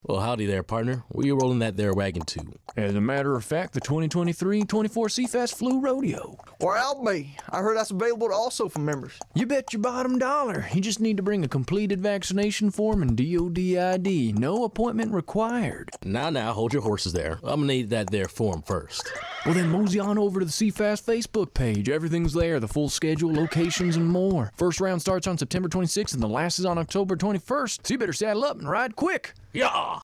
A radio spot for AFN Sasebo, the Eagle, to promote the CFAS Branch Health Clinics 2023-2024 "Flu Rodeo" program.